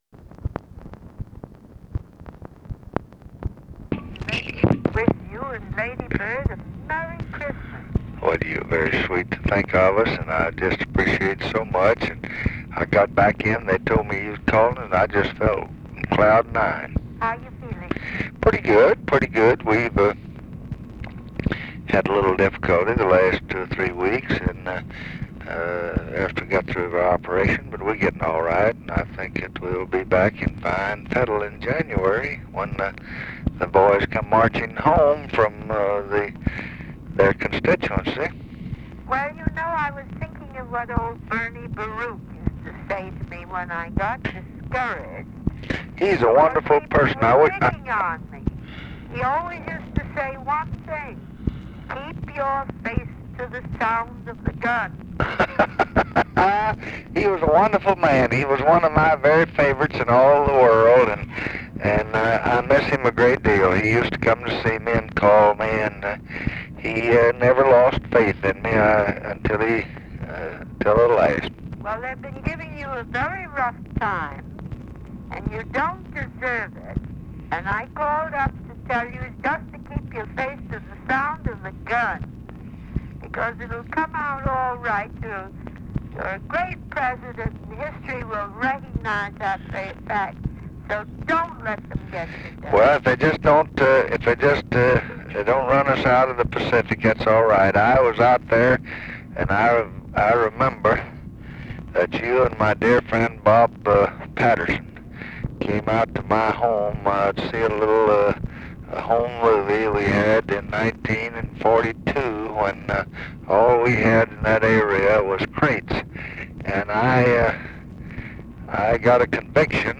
Conversation with CLARE BOOTH LUCE, December 28, 1966
Secret White House Tapes